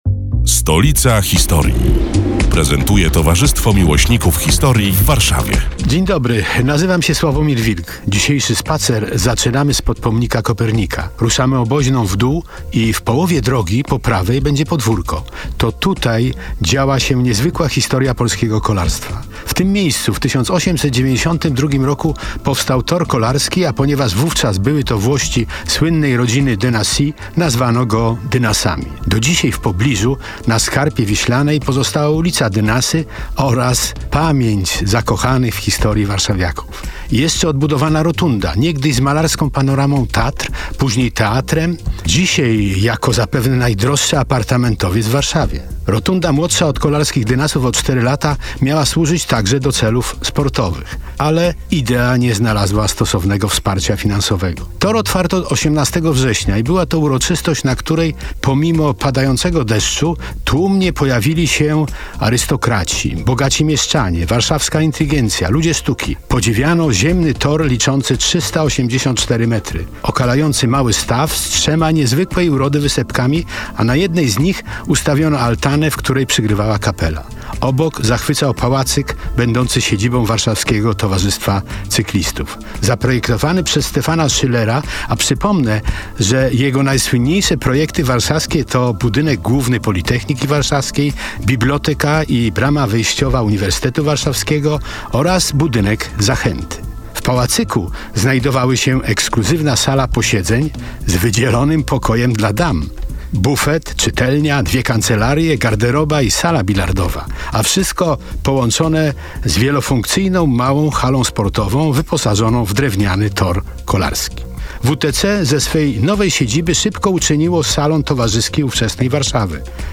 91. felieton pod wspólną nazwą: Stolica historii.